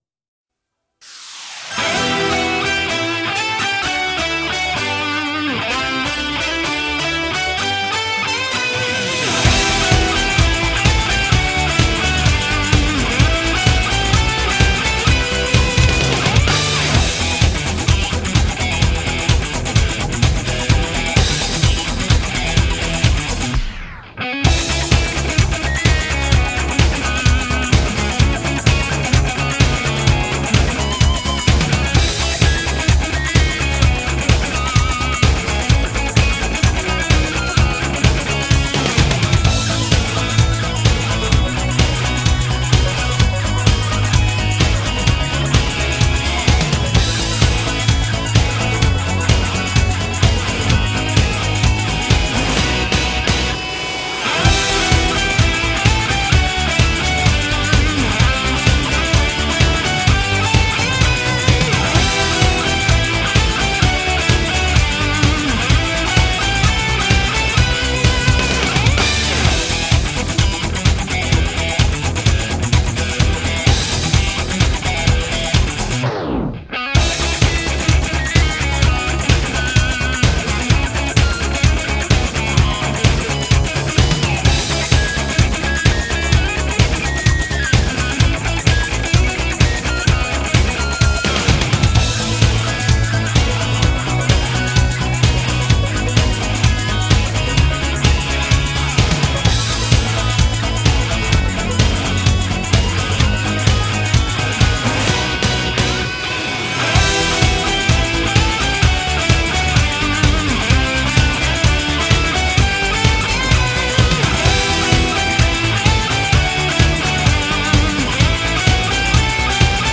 青春时尚